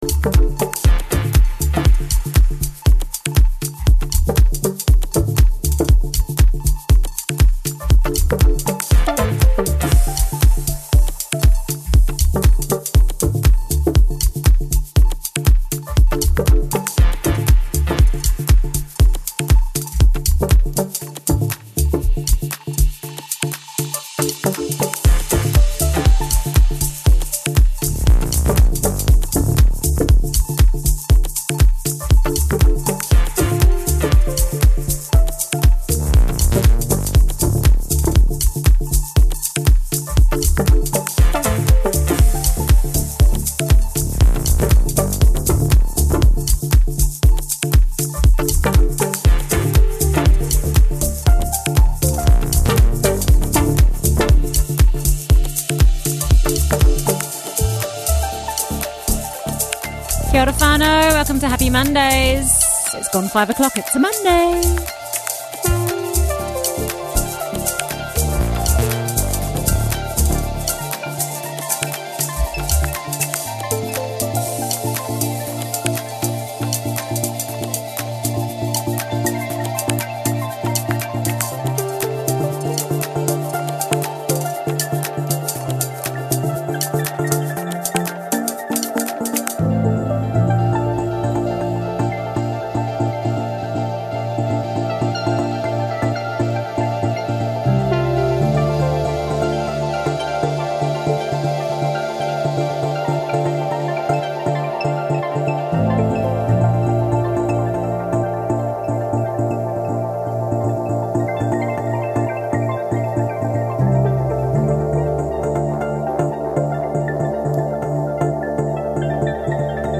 Pure nostalgic sunshine bangers House, dnb, garage and RAVE